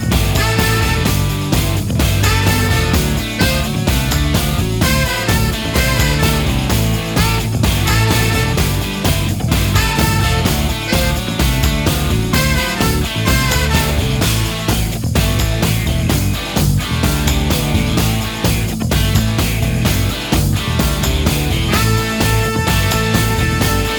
no Backing Vocals Indie / Alternative 3:39 Buy £1.50